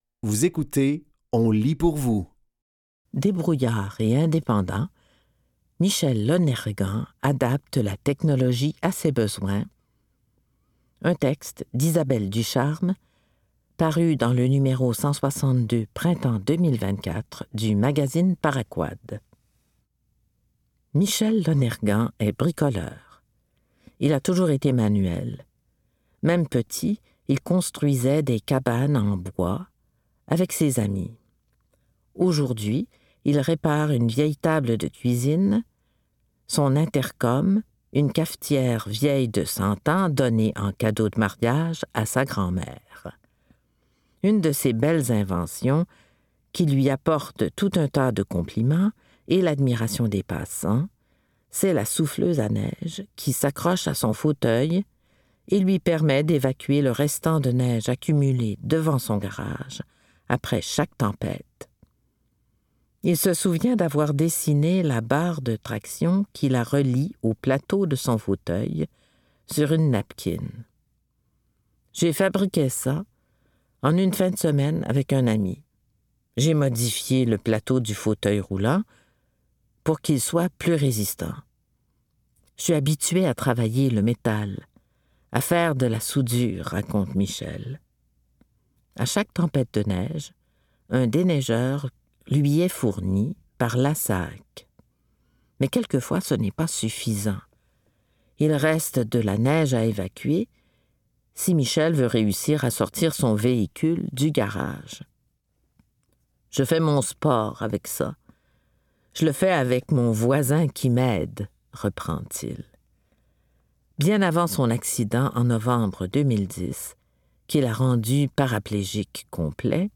Dans cet épisode de On lit pour vous, nous vous offrons une sélection de textes tirés du média suivant : Paraquad.